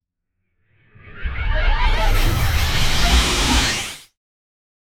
sci-fi-checkpoint-reached-dfcotjng.wav